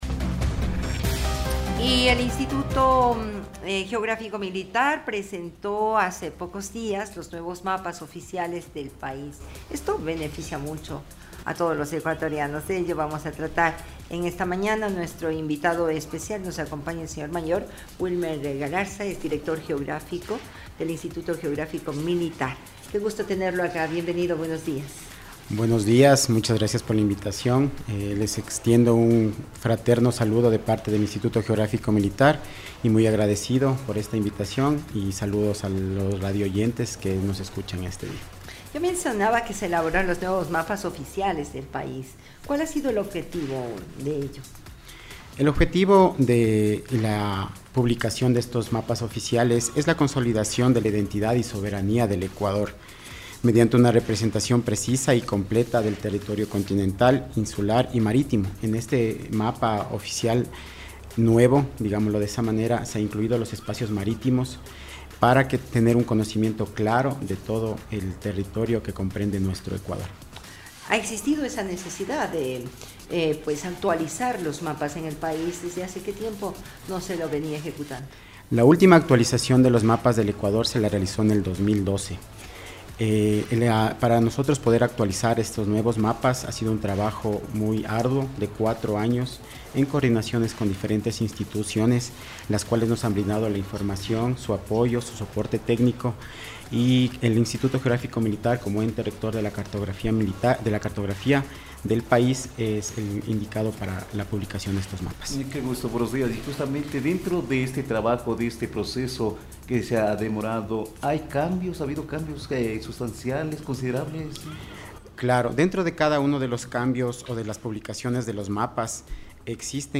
Escuche la entrevista completa a continuación: Dirección de Comunicación Social http